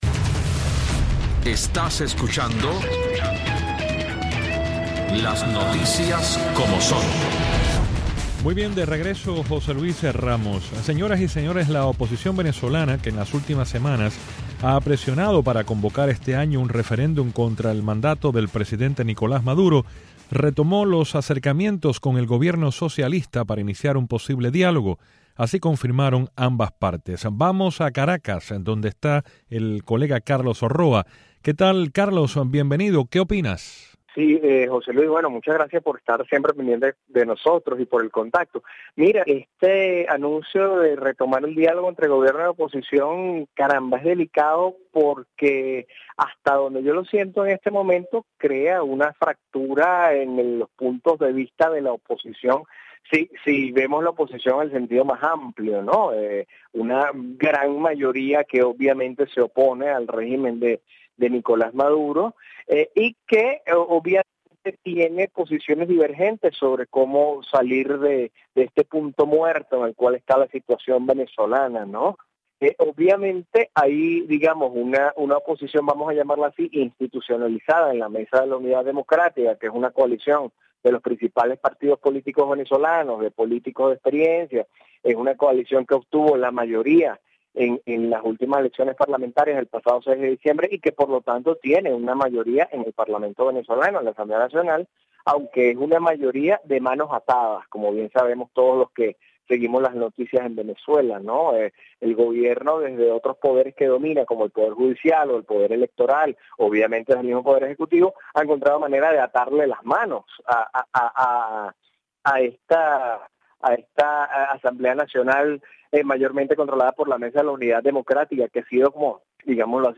Los bicitaxistas en La Habana Vieja y Centro Habana confrontan nuevos impuestos y una medida que prohíbe laborar en el área a los que no residan oficialmente en esos distritos. Abordamos esos cambios con dos bicitaxistas de La Habana.